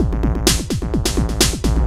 DS 128-BPM B4.wav